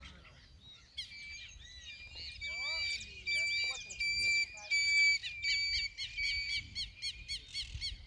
Whistling Heron (Syrigma sibilatrix)
Country: Argentina
Province / Department: Entre Ríos
Condition: Wild
Certainty: Observed, Recorded vocal